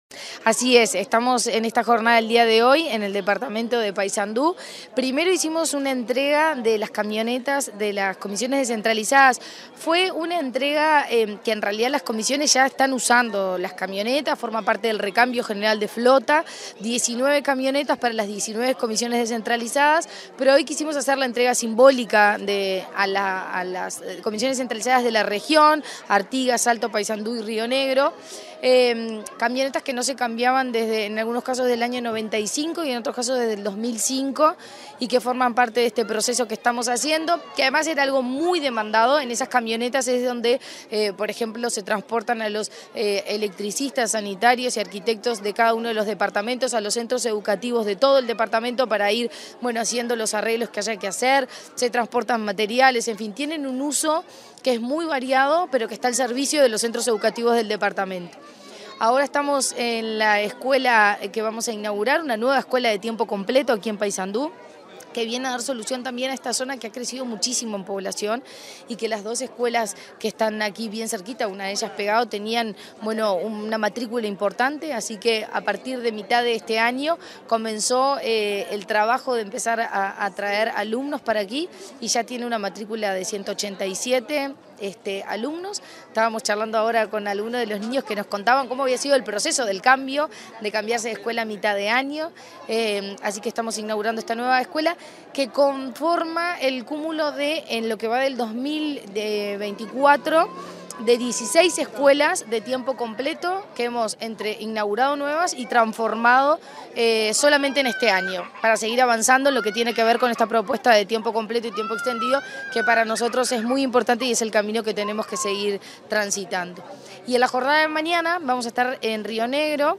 Entrevista a la presidenta de ANEP, Virginia Cáceres
Comunicación Presidencial dialogó con la presidenta del Consejo Directivo Central (Codicen) de la Administración Nacional de Educación Pública (ANEP),
en el marco de la inauguración de una escuela de tiempo completo en Paysandú